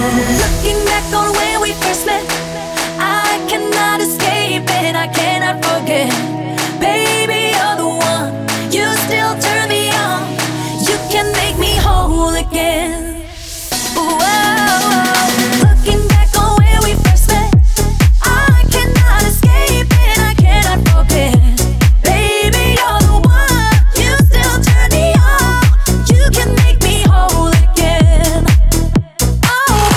Dance
Жанр: Танцевальные